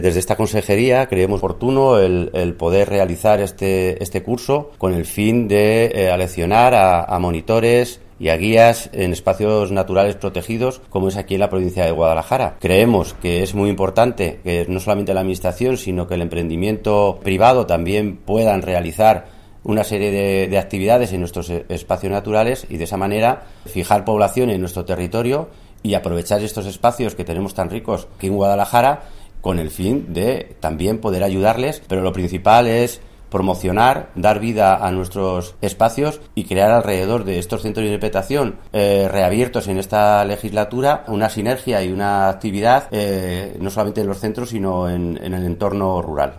El director provincial de Agricultura, Medio Ambiente y Desarrollo Rural en Guadalajara, Santos López, habla del curso de monitores medioambientales organizado por el Gobierno regional.